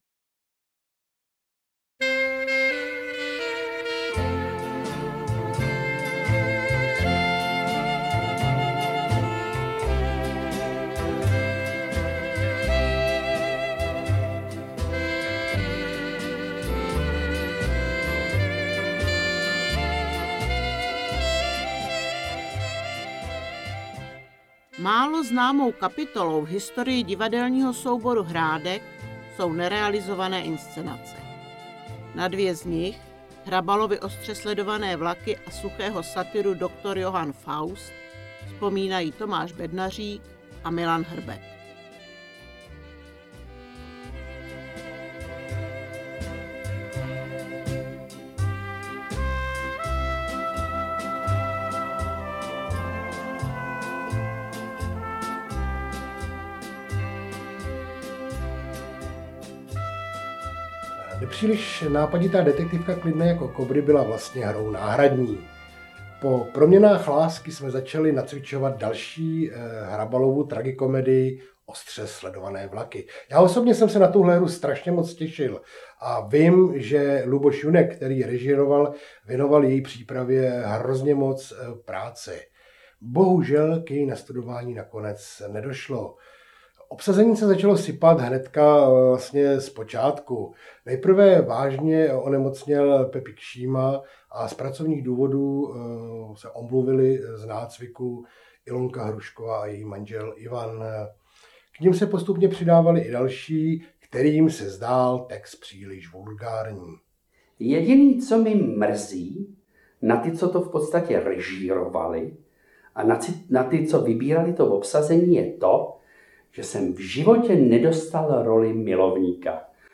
Komentované listinné a obrazové dokumenty, vzpomínky křivoklátských ochotníků.